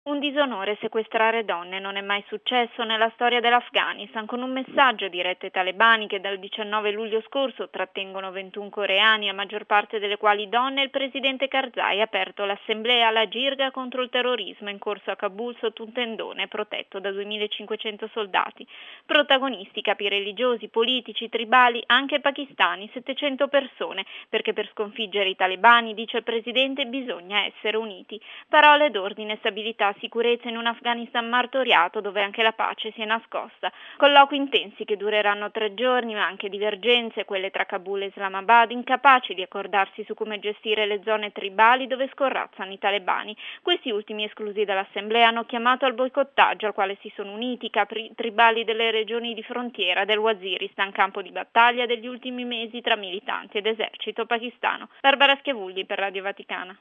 Una vergogna storica il rapimento di donne in Afghanistan, il presidente Karzai ha così bollato il sequestro delle 18 coreane da parte dei talebani, il 19 luglio scorso. Karzai lo ha detto aprendo a Kabul i lavori della jirga per la pace, con i leader tribali afghani e pachistani. Servizio